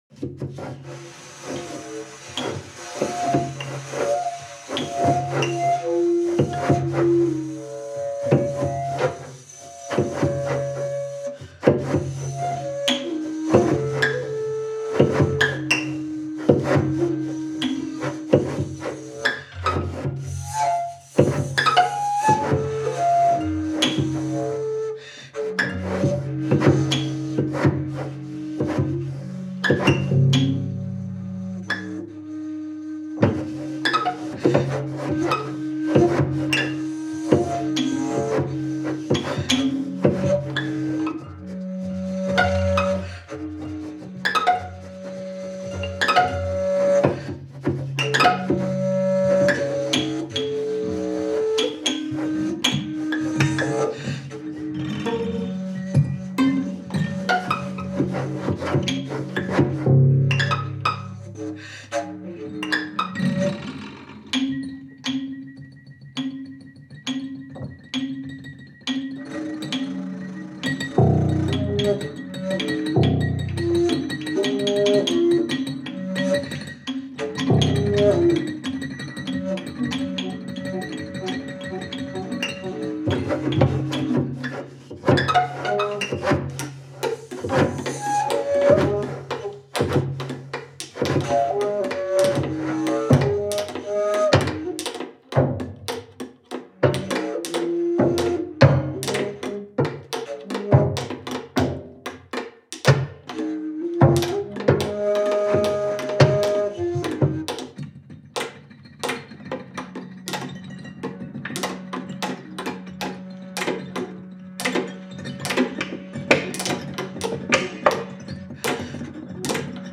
prepared piano
viola
flute, bass flute